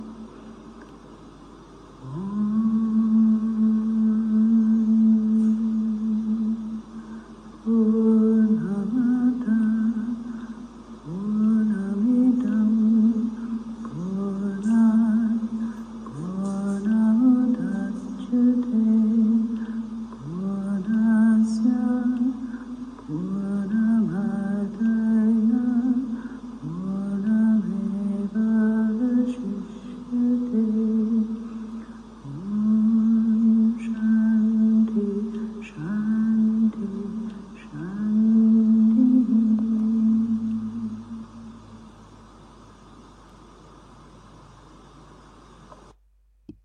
Chants in Sanskrit
Pūrṇam Adaḥ, Pūrṇam Idam (Devotional, Field recording, mono)